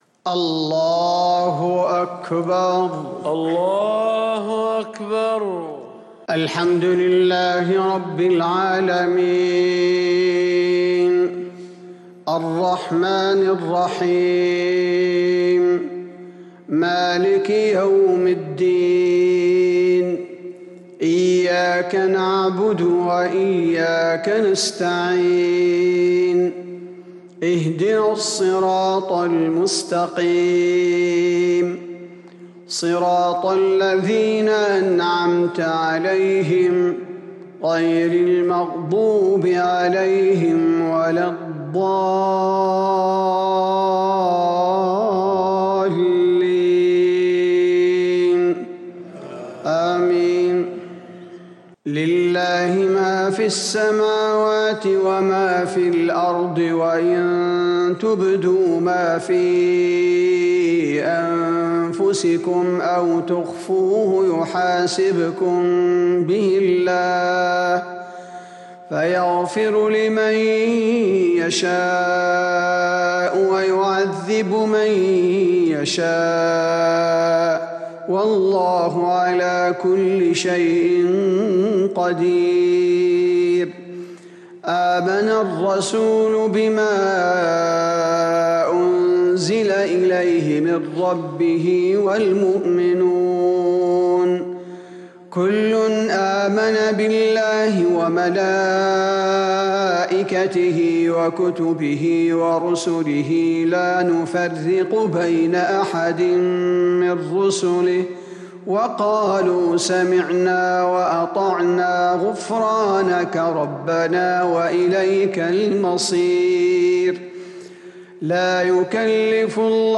صلاة العشاء للقارئ عبدالباري الثبيتي 11 جمادي الأول 1442 هـ
تِلَاوَات الْحَرَمَيْن .